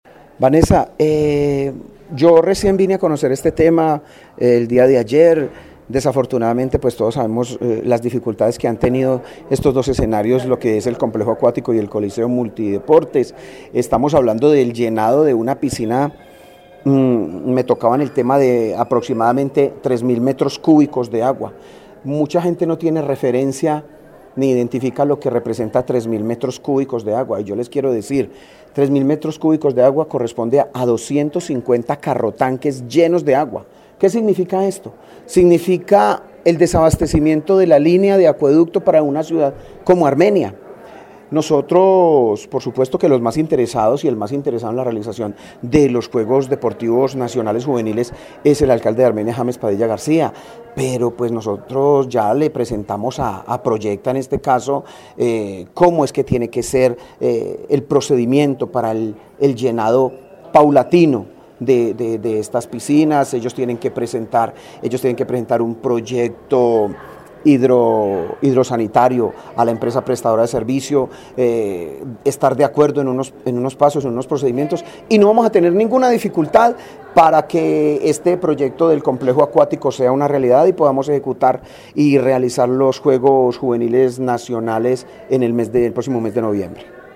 Alcalde de Armenia